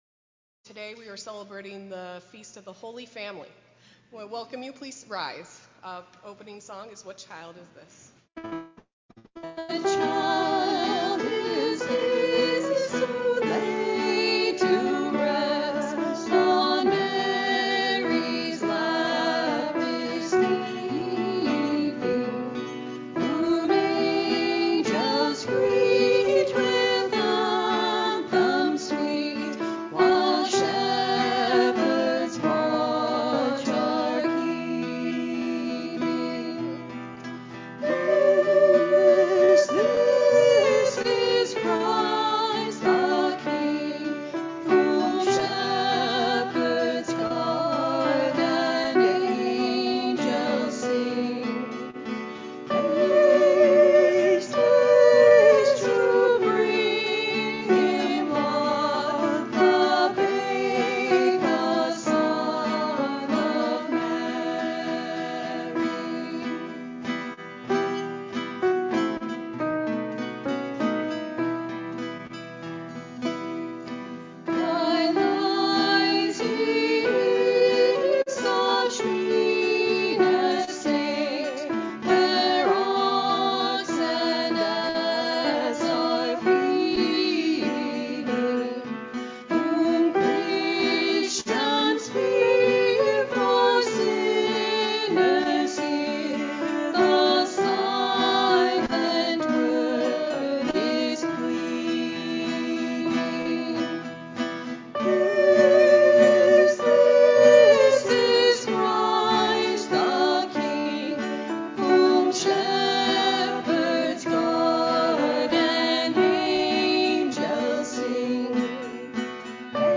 2020-12-27-Holy-Family-Mass-Audio-CD.mp3